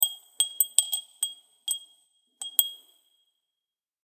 ガラス風鈴2.mp3